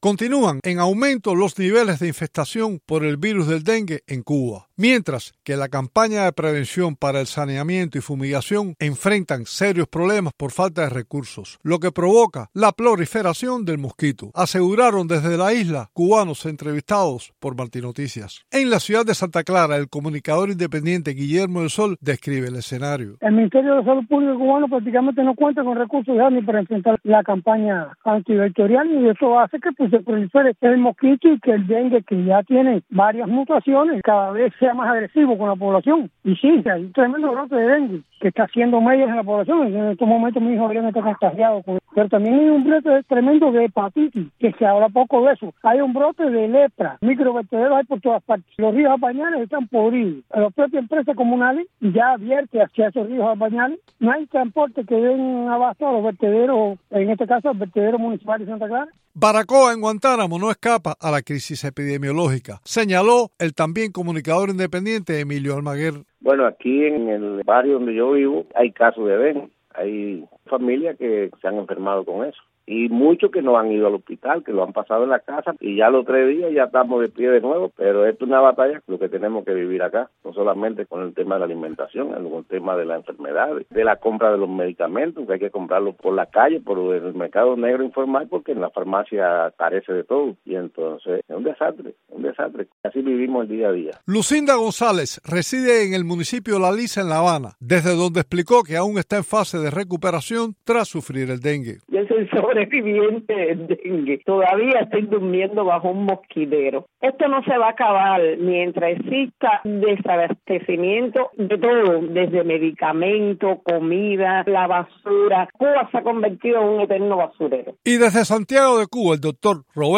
Desde la isla, varios cubanos entrevistados por Martí Noticias, alertaron sobre la proliferación de los focos del mosquito Aedes Aegypti, transmisor del dengue y advirtieron que los niveles de infestación por el virus van en aumento.